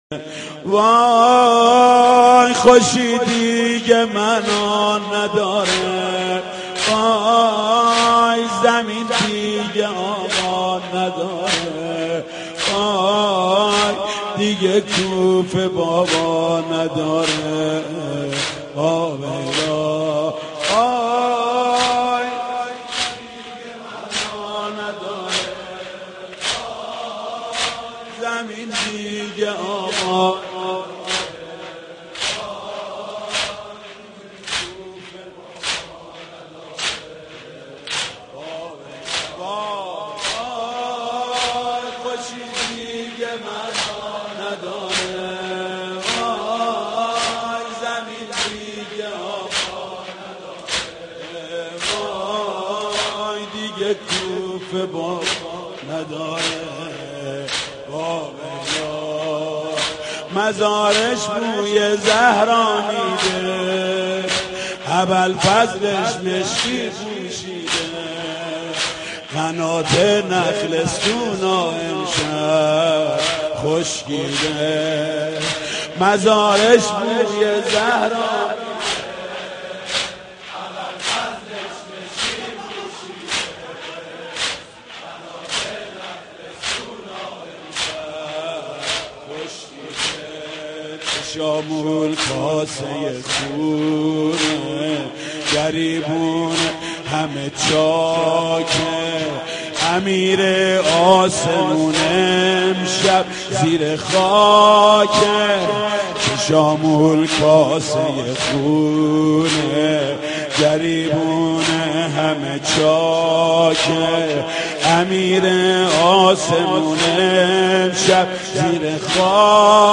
رمضان 89 - سینه زنی
رمضان-89---سینه-زنی